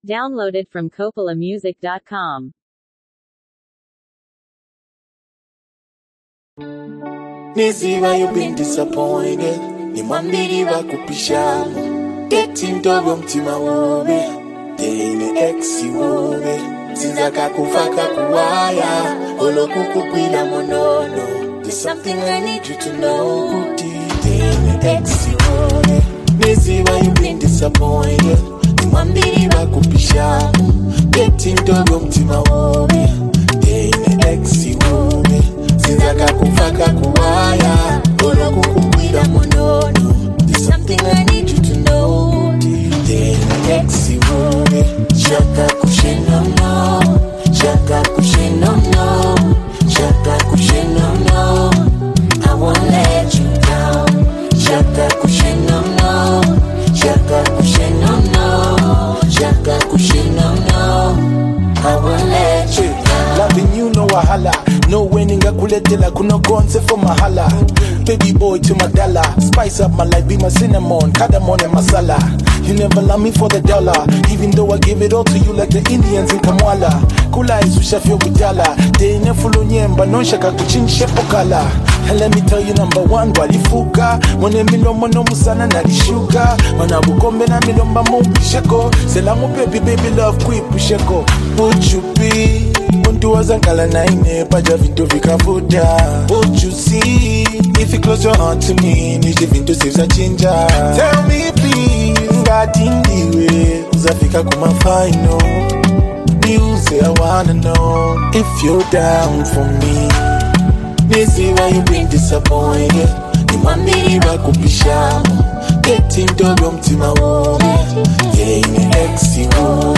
Zambian Music
a powerful and emotional song
emotional vocals